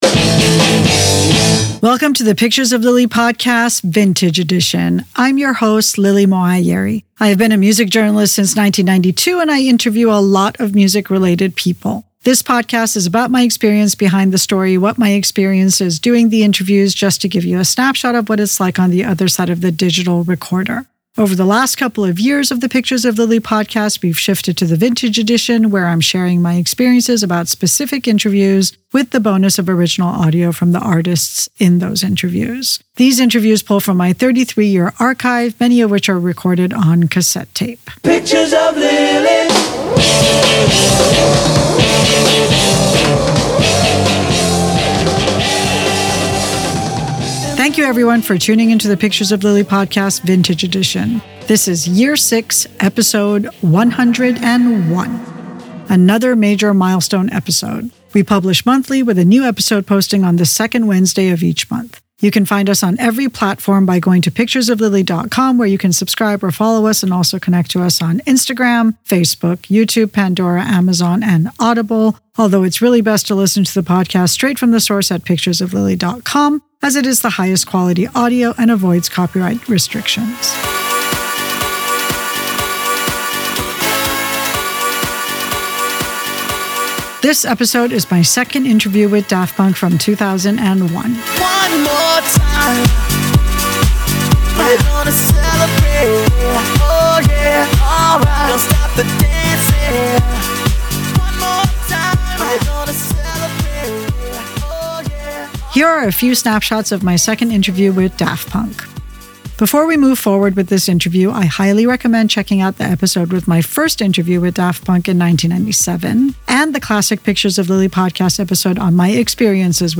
Daft Punk 2001 Interview